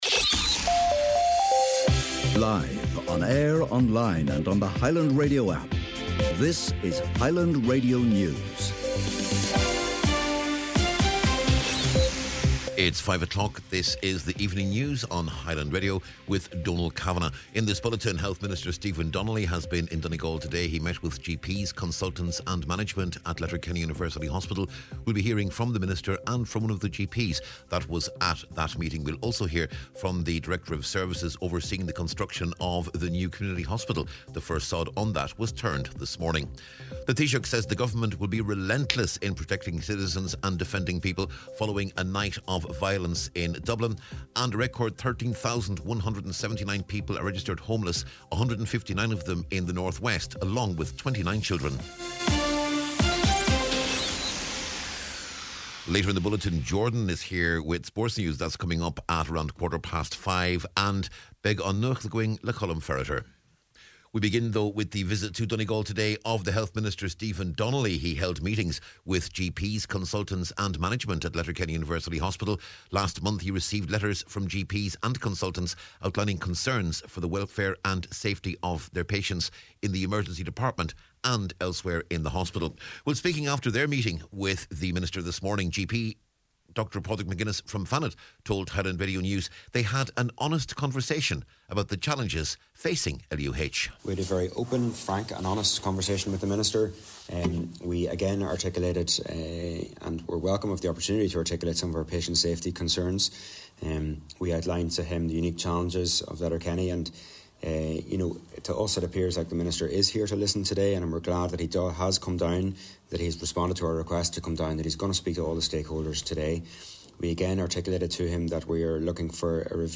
News, Sport, Nuacht, and Obituaries on Friday November 24th